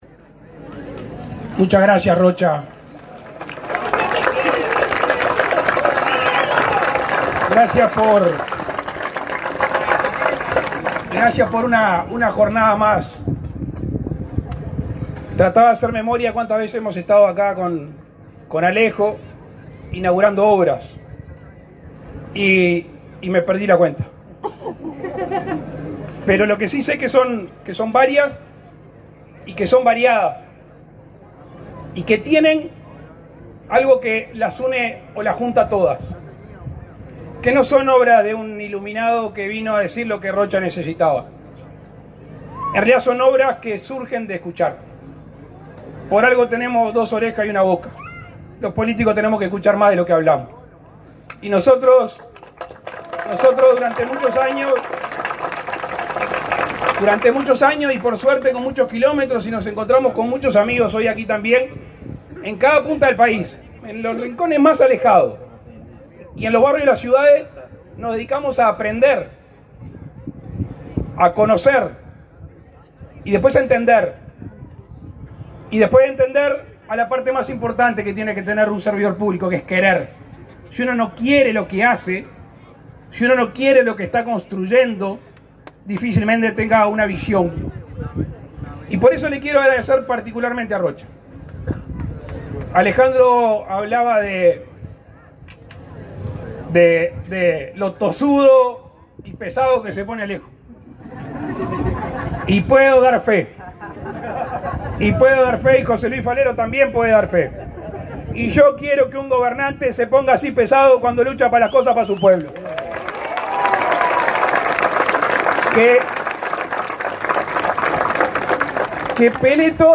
Palabras del presidente de la República, Luis Lacalle Pou
En el puente Paso Real, el mandatario realizó declaraciones.